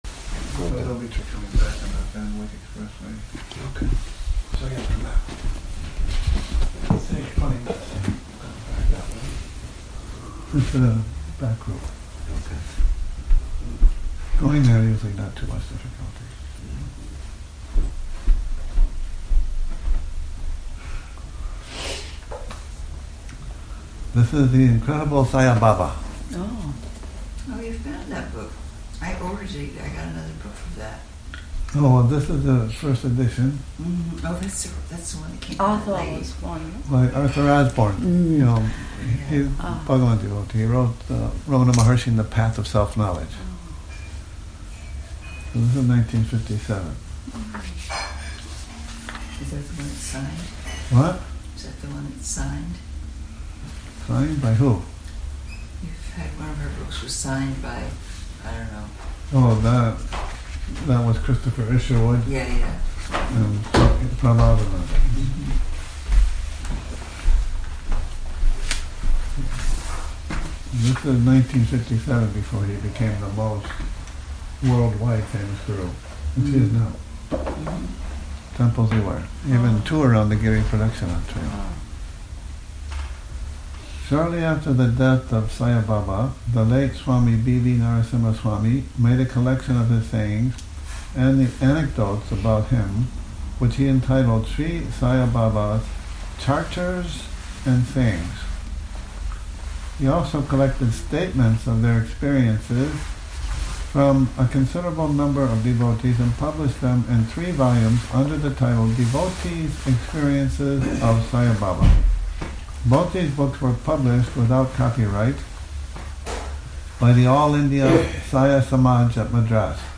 Morning Reading, 19 Sep 2019
a reading from "The Incredible Sai Baba" by Arthur Osborne, Morning Reading, 19 Sep 2019